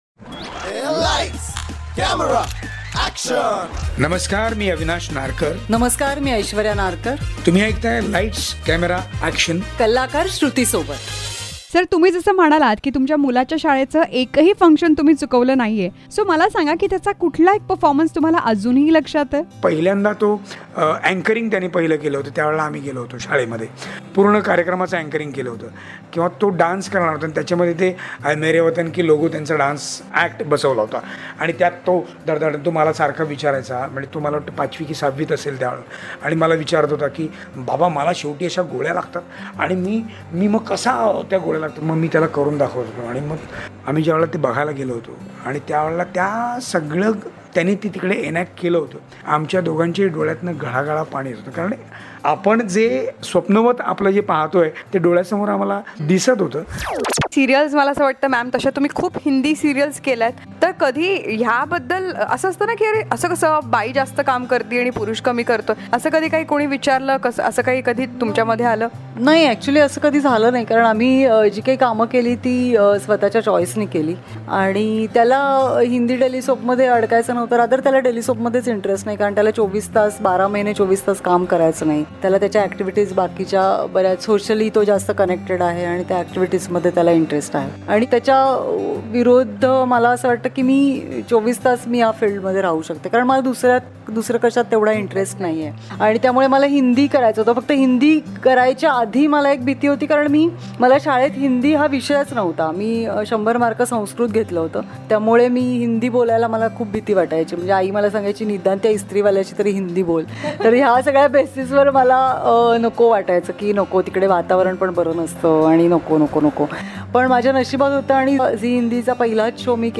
Love is a beautiful journey where every step is a destination and every moment eternity .. Listen to this podcast as the cutest romantic couple talks about their journey of life exclusively on Lights Camera Action.